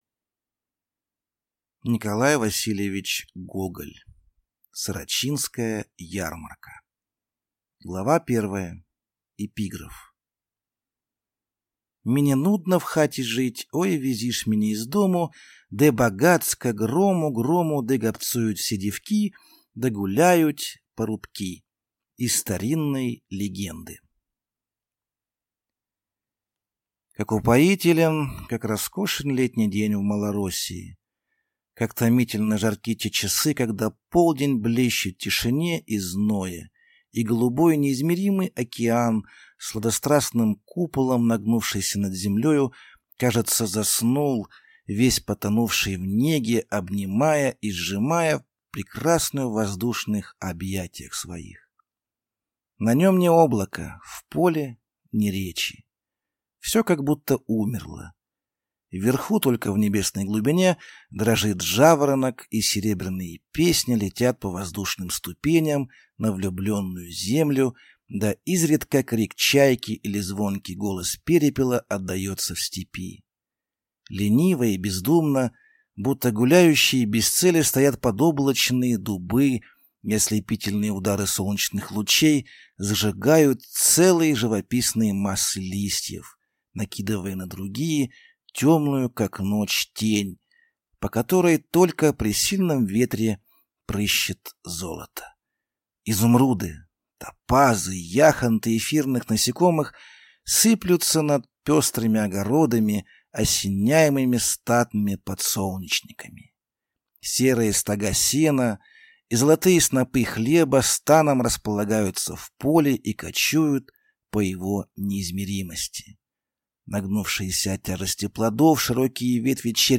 Аудиокнига Сорочинская ярмарка | Библиотека аудиокниг